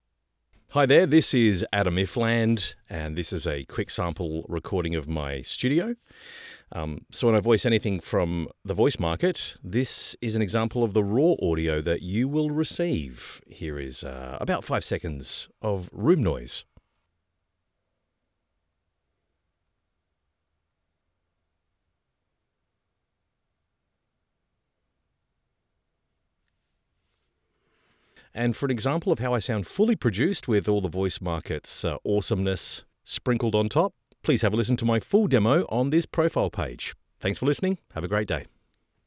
Full Demo